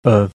e in regarder
a in amend